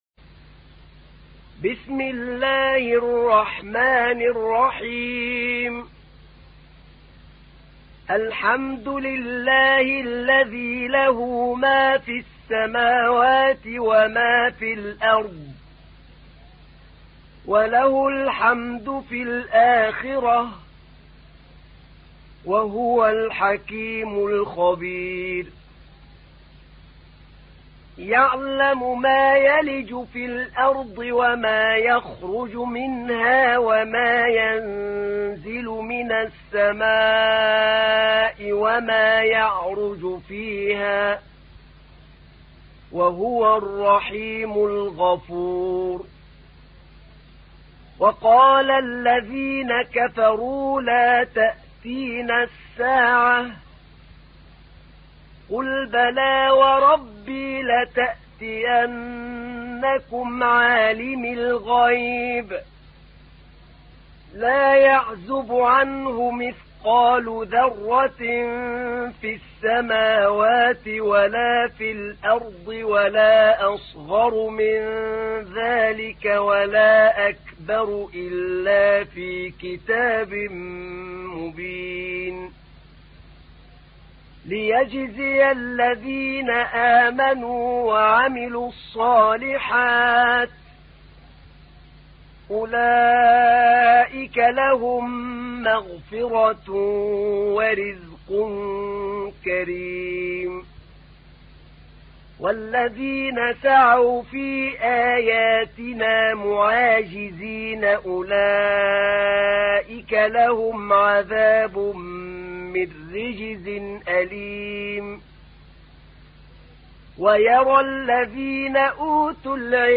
تحميل : 34. سورة سبأ / القارئ أحمد نعينع / القرآن الكريم / موقع يا حسين